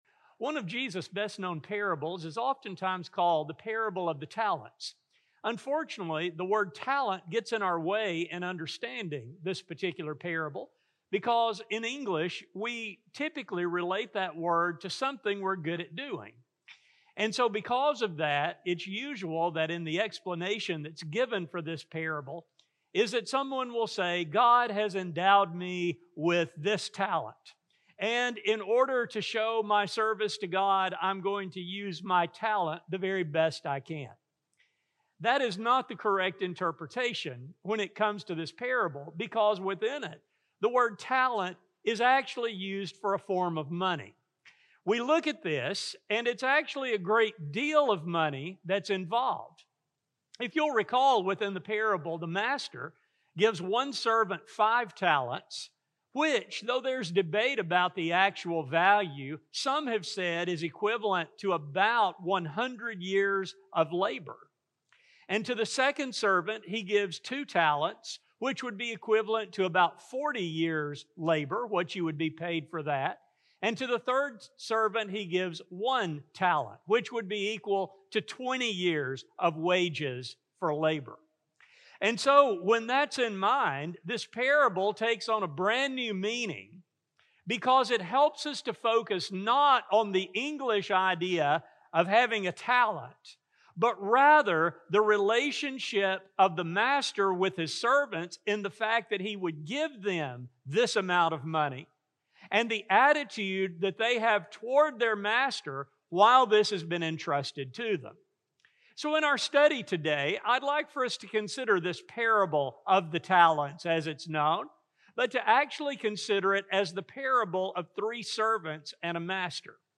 A sermon recording